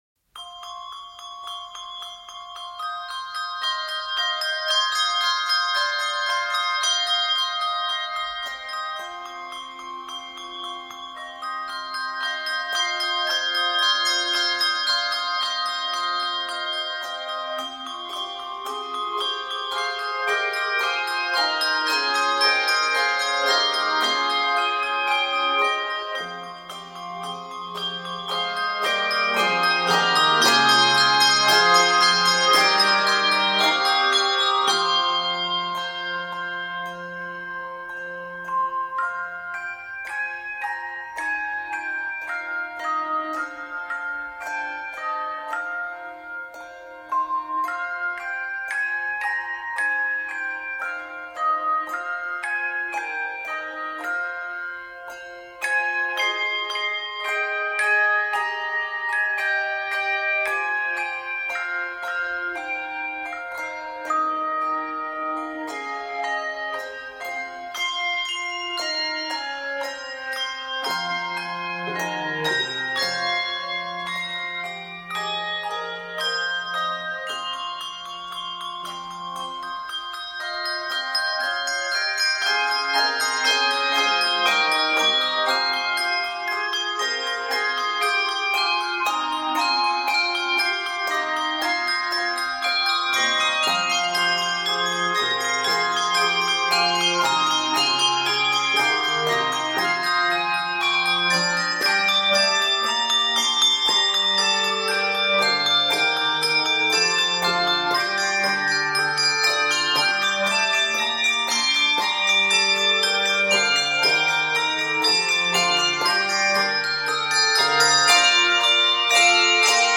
Scored in f minor and g minor, it is 80 measures.